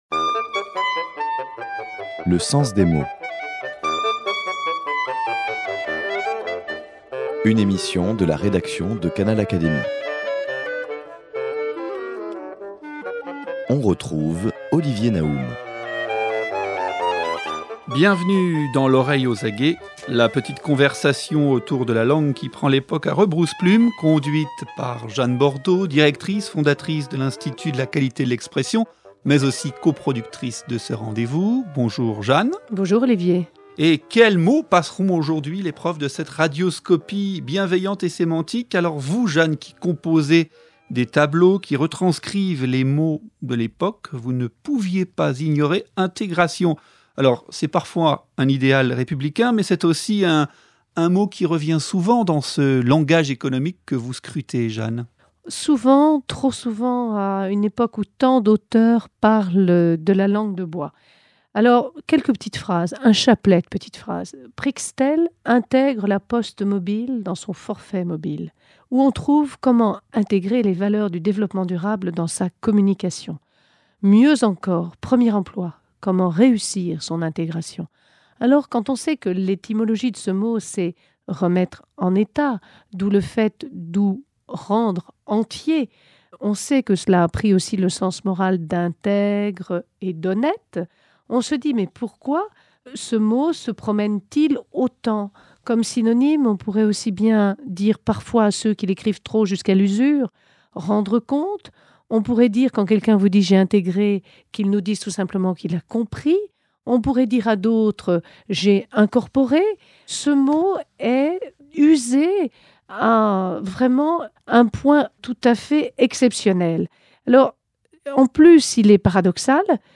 la chronique qui prend les mots à rebrousse-plume